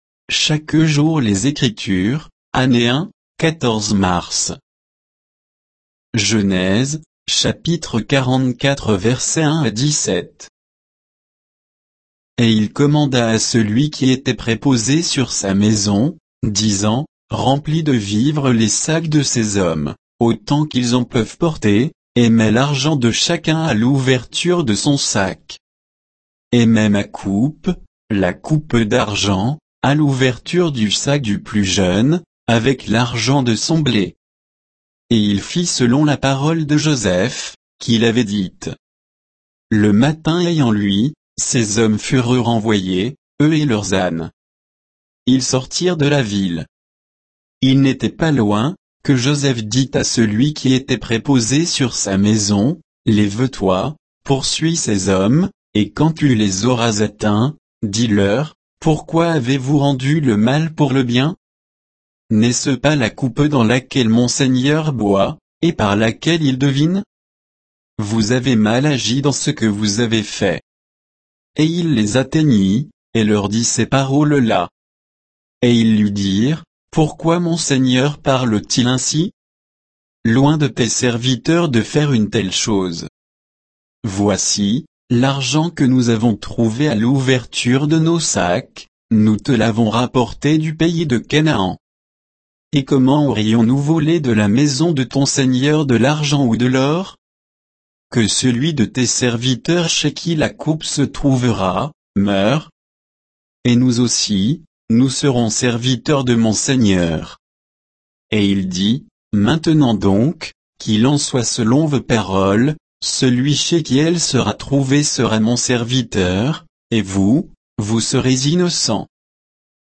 Méditation quoditienne de Chaque jour les Écritures sur Genèse 44